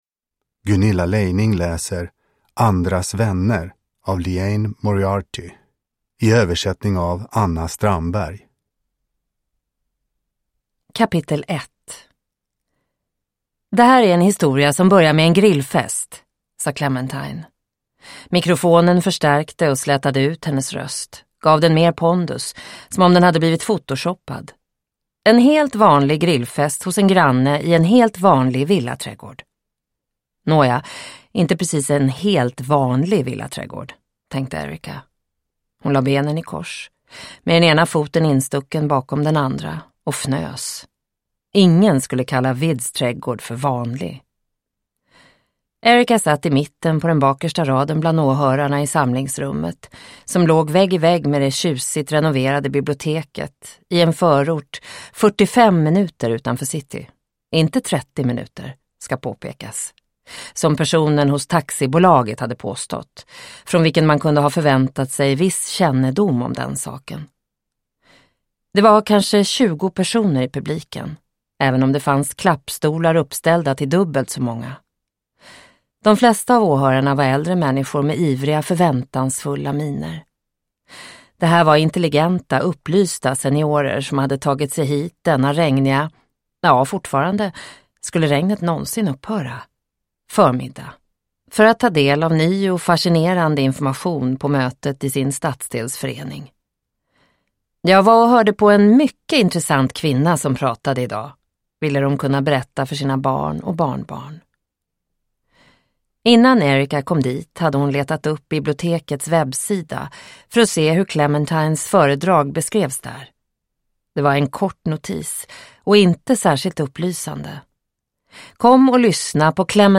Andras vänner – Ljudbok – Laddas ner